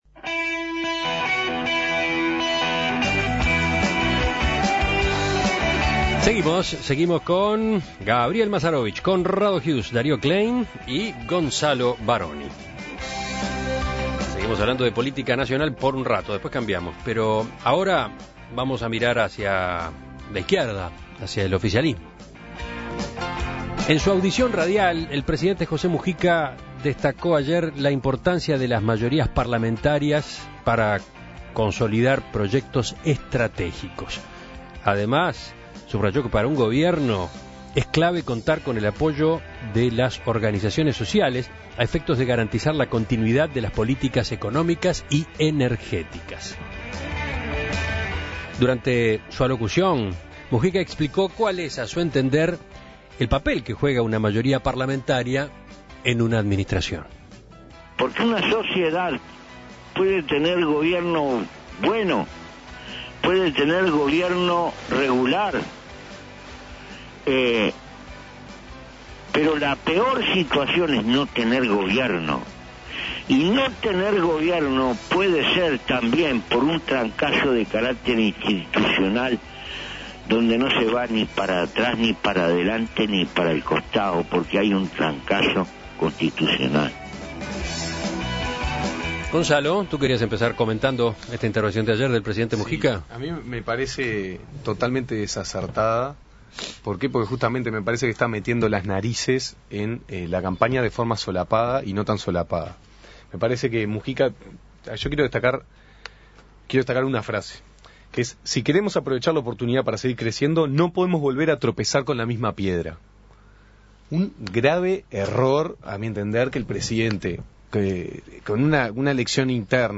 José Mujica destacó en su audición radial la importancia de las mayorías parlamentarias para consolidar proyectos estratégicos